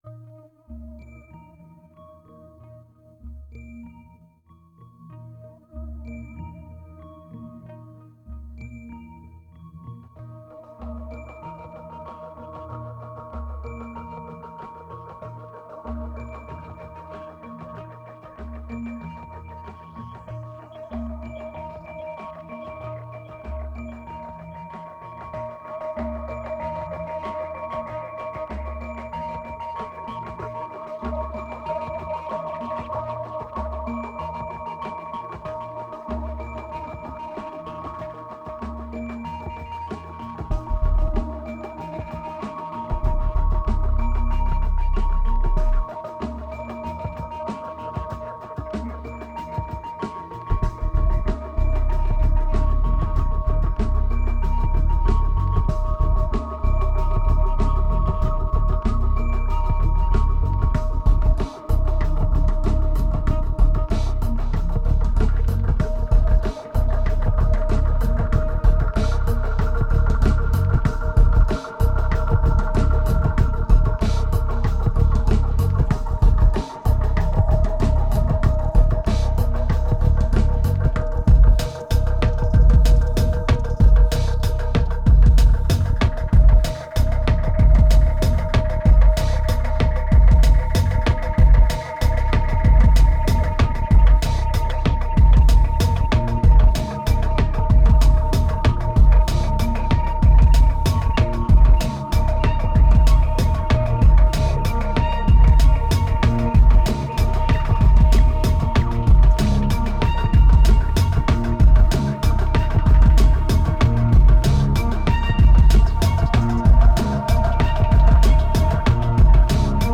2355📈 - 3%🤔 - 95BPM🔊 - 2010-09-15📅 - -138🌟